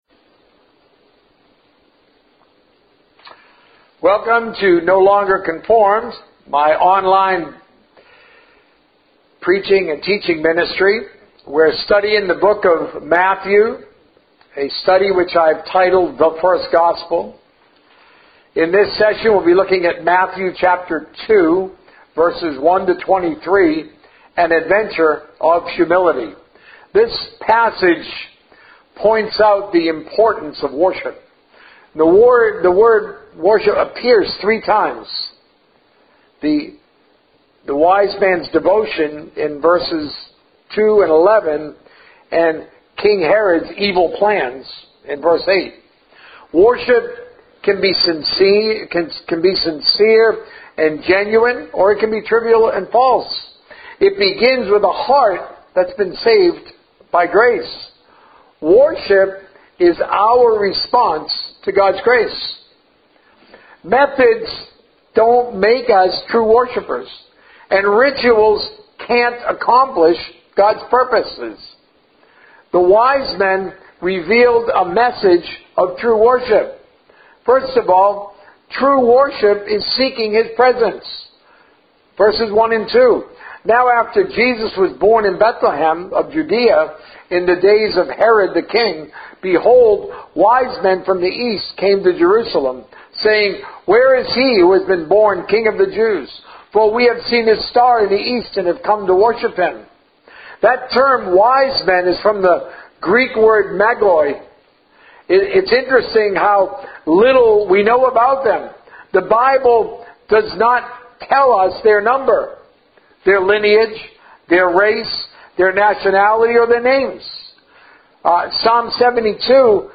A message from the series "The First Gospel." Marriage and Divorce - Part 1